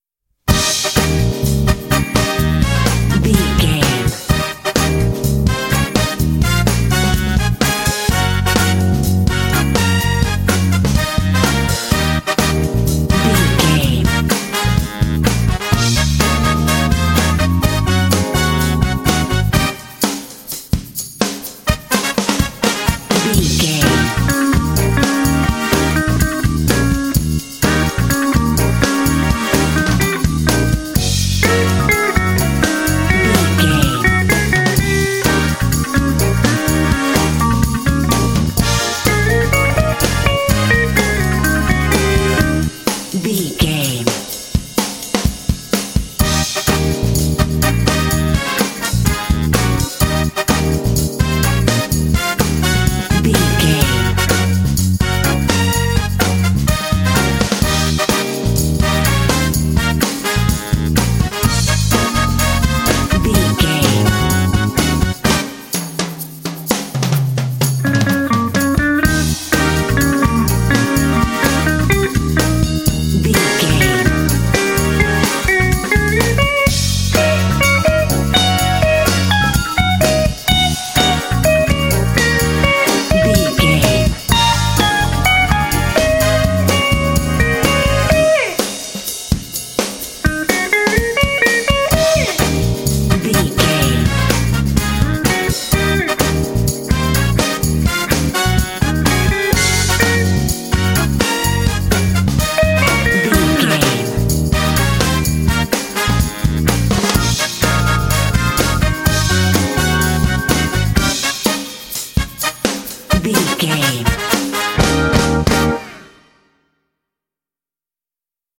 Aeolian/Minor
E♭
funky
happy
bouncy
groovy
bass guitar
drums
electric guitar
electric organ
brass
electric piano
jazz
blues